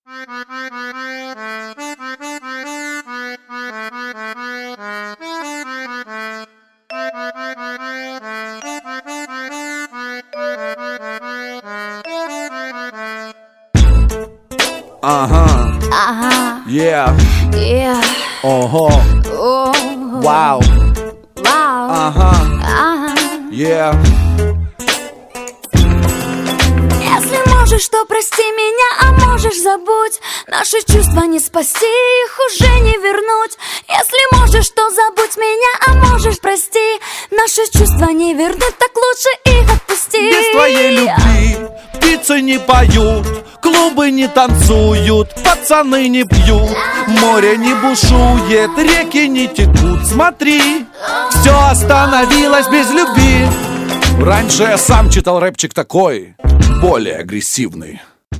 • Качество: 128, Stereo
поп
спокойные
дуэт